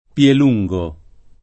[ p L el 2jg o ]